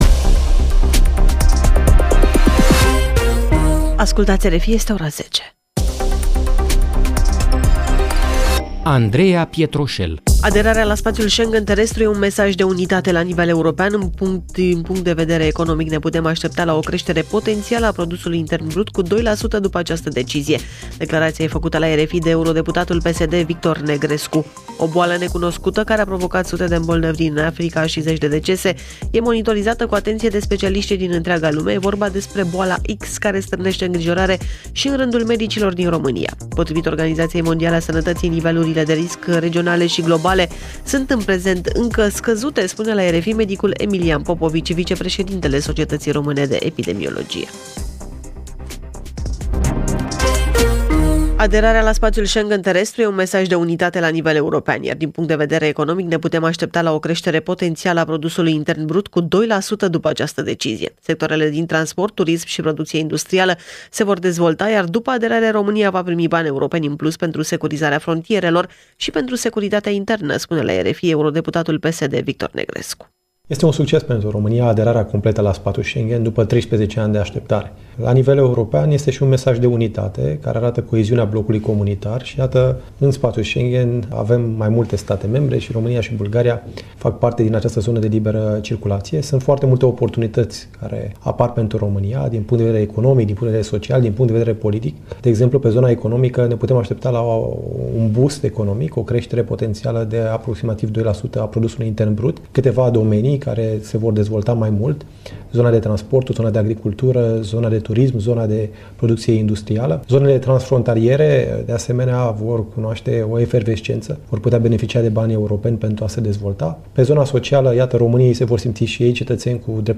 Jurnal de știri